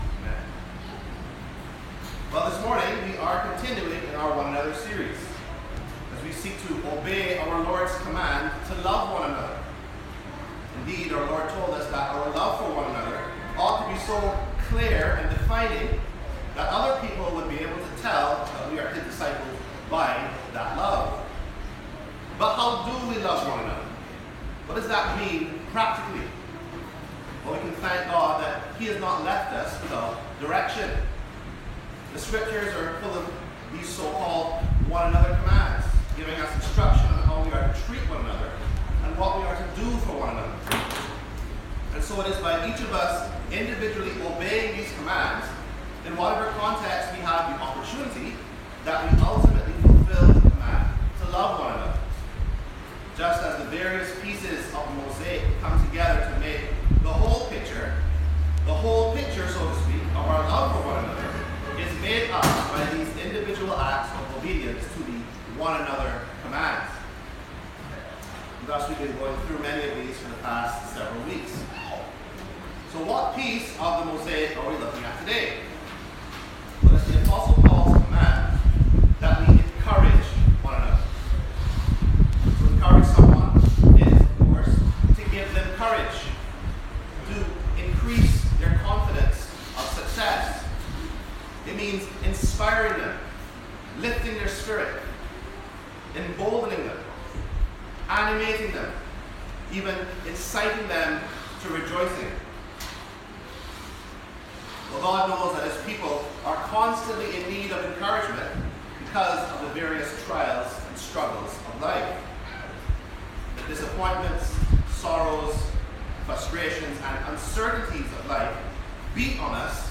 Sermons Podcast - Encourage One Another | Free Listening on Podbean App